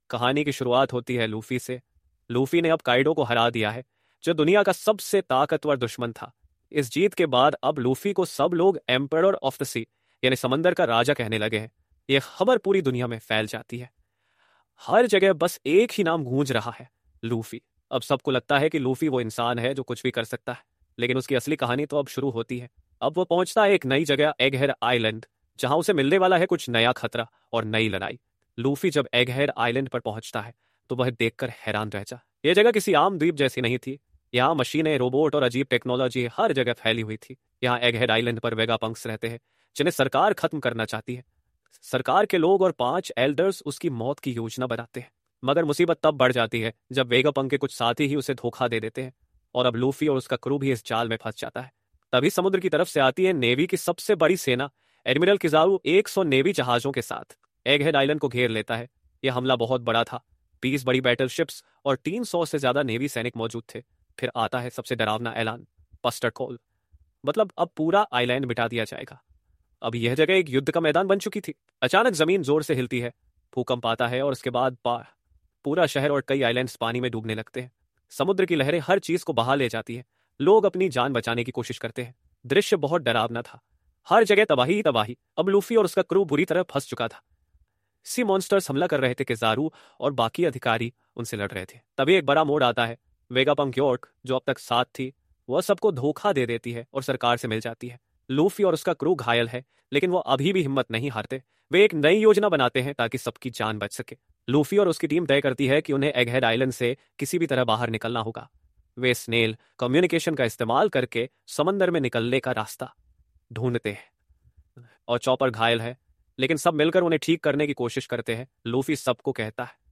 Dialogue_Delivery___1.2x.mp3